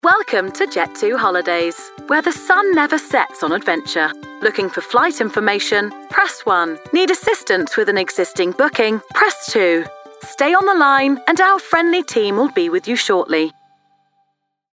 English (British)
Versatile, Friendly, Natural
Telephony